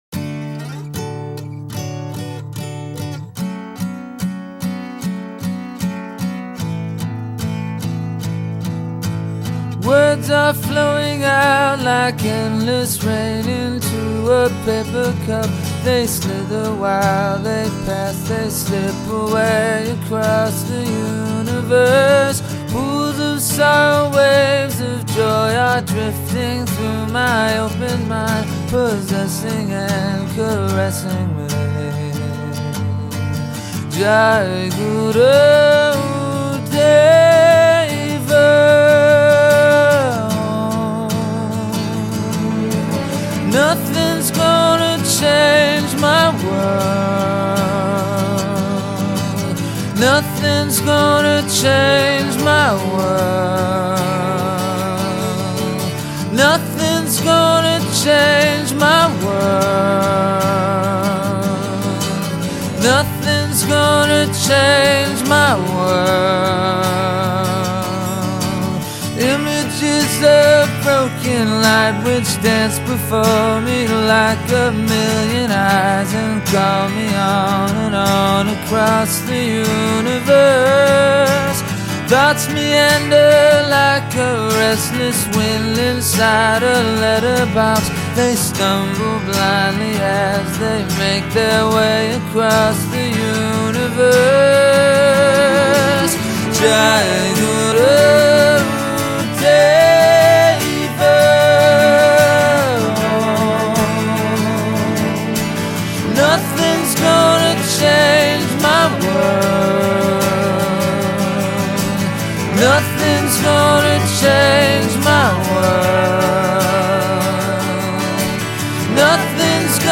I recommend headphones, some dope and a darkened room.